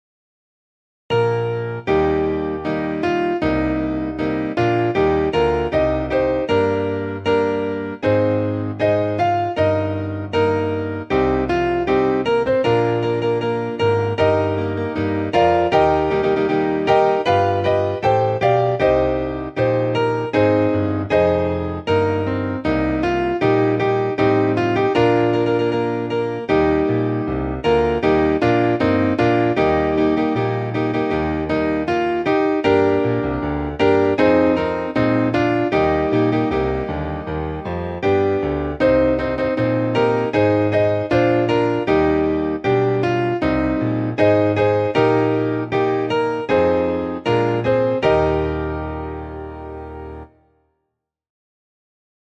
Our_Republic_Piano.mp3